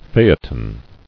[pha·e·ton]